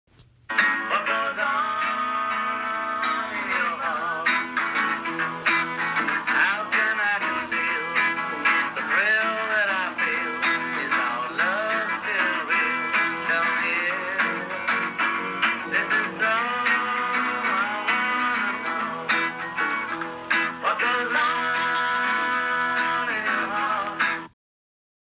Platen inneholder en uutgitt og uhørt hjemmedemo av e…